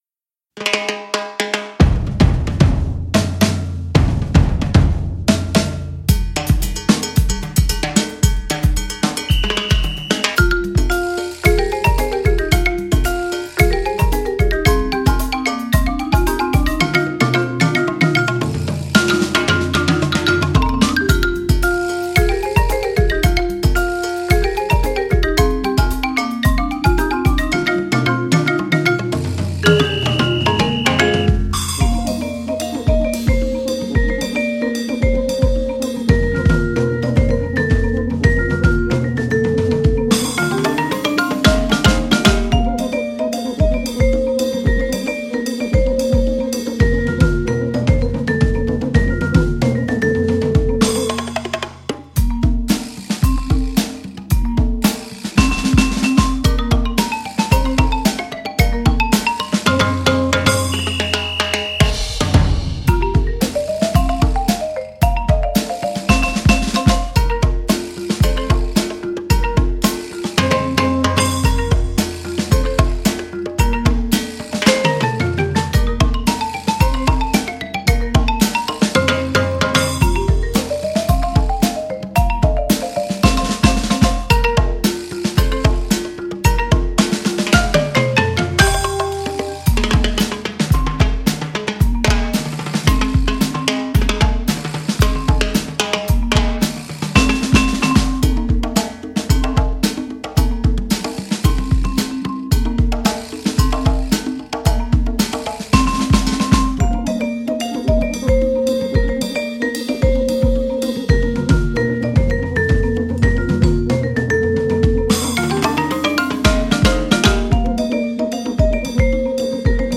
Voicing: 8-9 Percussion